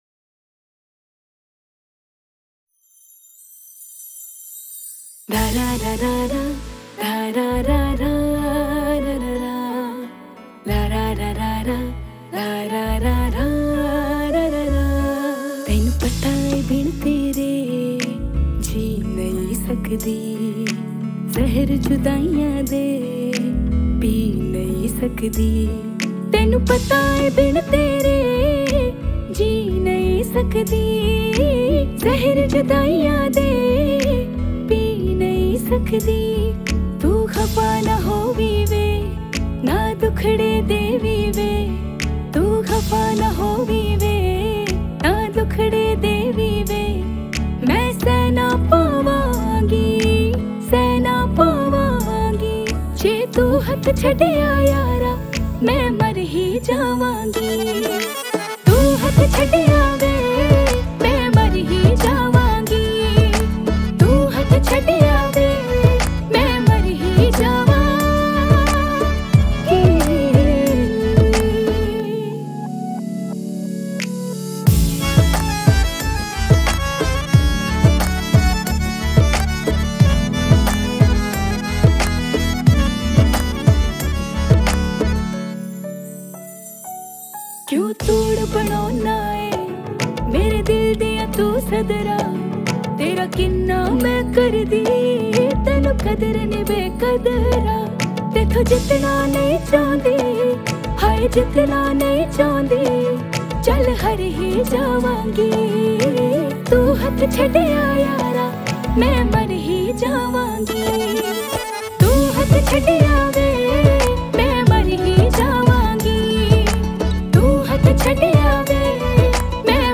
Single Indian Pop